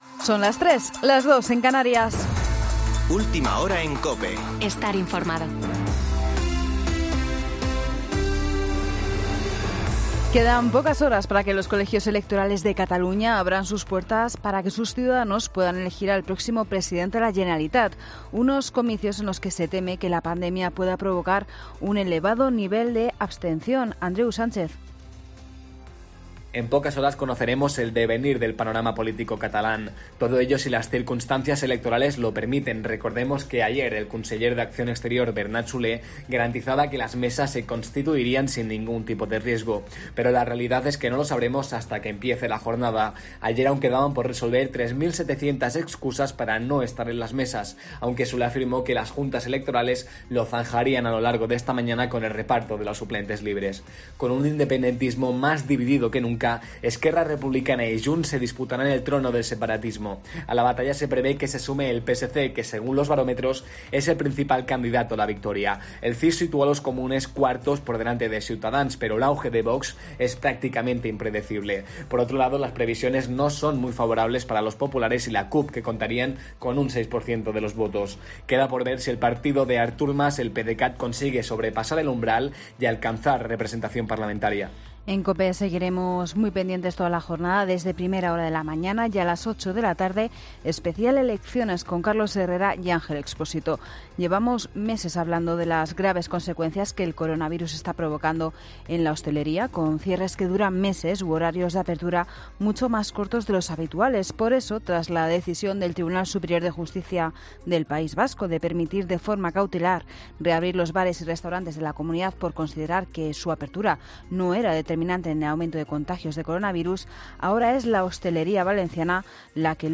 Boletín de noticias COPE del 14 de febrero de 2021 a las 03.00 horas